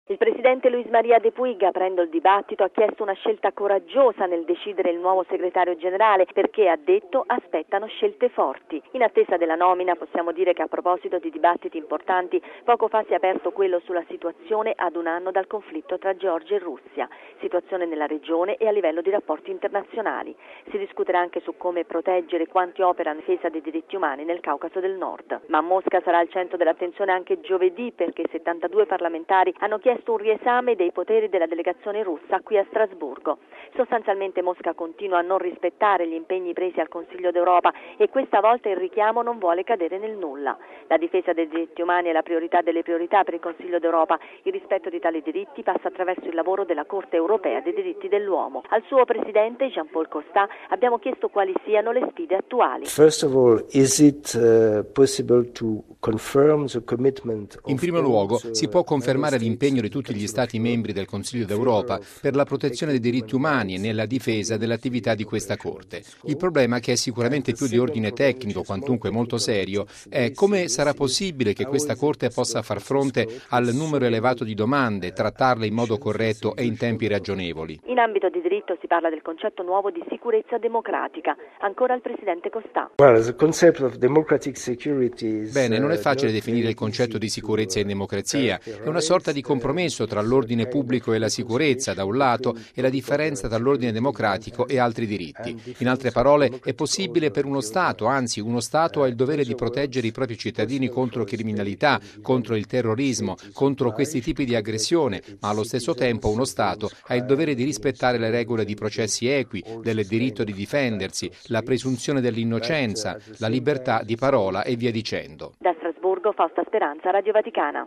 Al Consiglio d'Europa di Strasburgo la questione del Caucaso e la difesa dei diritti. Intervista col presidente della Corte Europea, Jean-Paul Costa